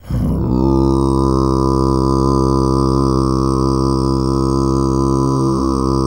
TUV3 DRONE01.wav